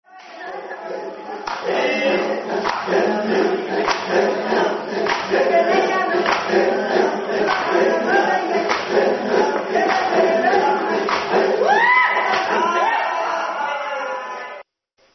今日はここで、マサイ・ダンスのショーが見れると
行ったときには既に始まっており、民族衣装をまとった若いマサイ達が、杖を持って、
マサイの踊りの歌